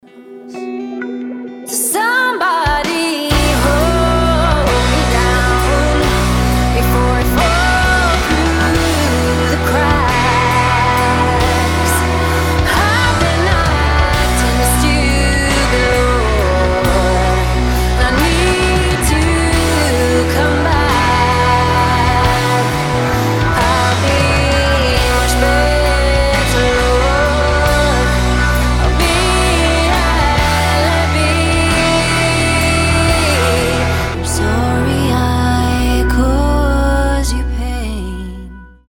красивый женский голос
сильный голос
Мажор